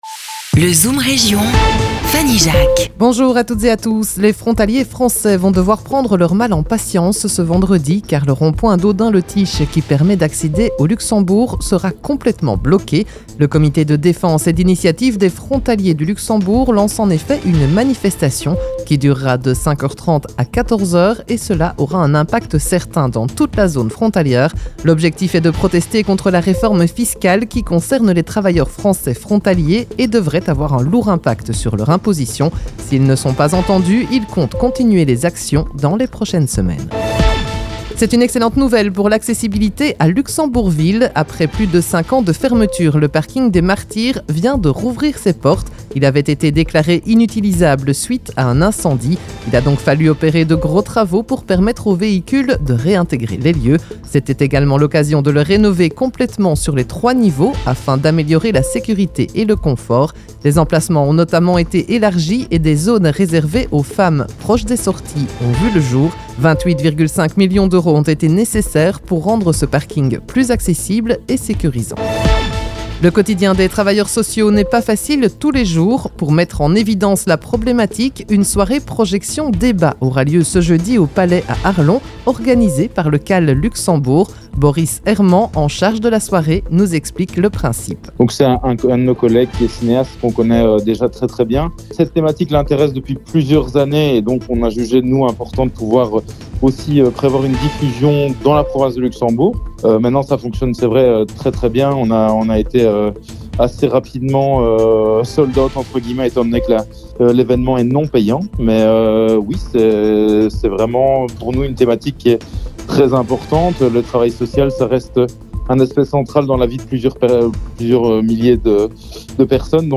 L'info en Grande Région